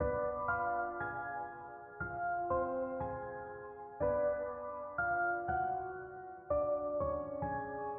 Short Trap Piano
标签： 120 bpm Trap Loops Piano Loops 1.35 MB wav Key : Unknown
声道立体声